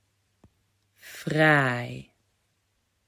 Sound module The ‘aai’ sound Recognizing and making the ‘aai’ sound ‘aai’ ✏ Assignment 4: Which sound do you hear?
Fraai.mp3